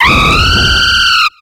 Cri de Flambusard dans Pokémon X et Y.